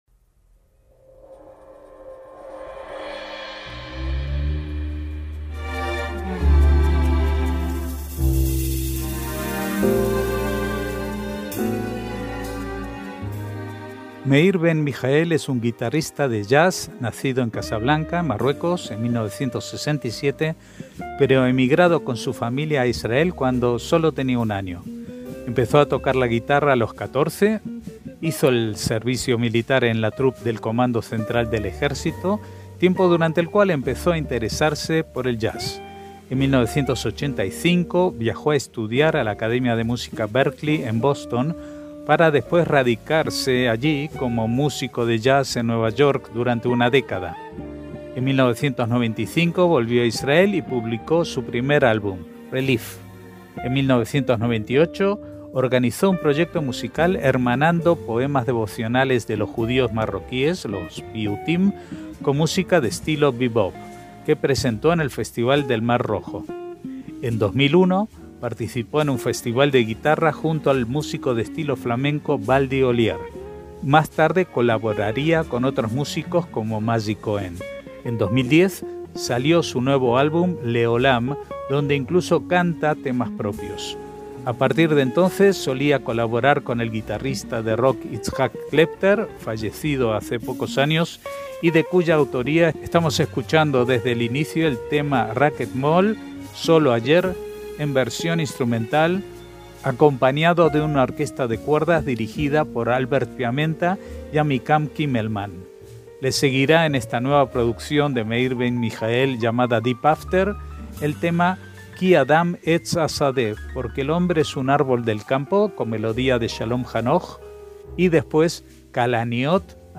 MÚSICA ISRAELÍ